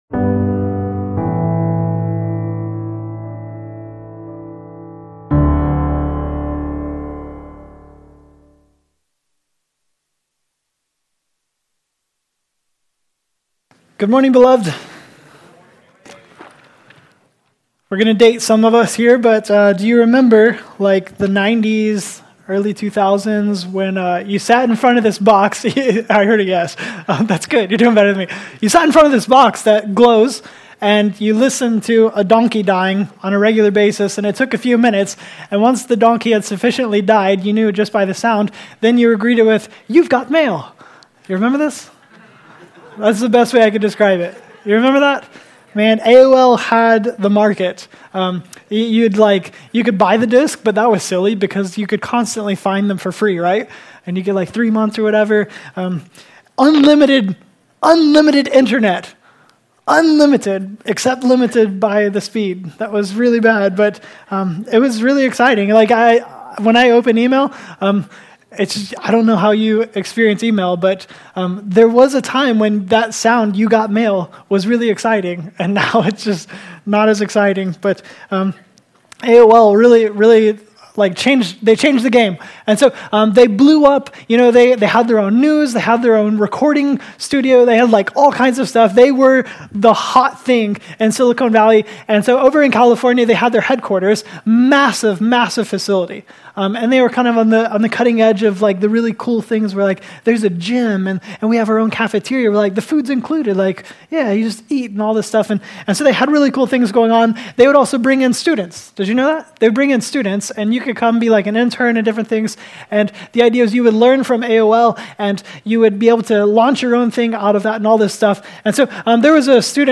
Listen to the first message in our fall series, CHOSEN EXILES.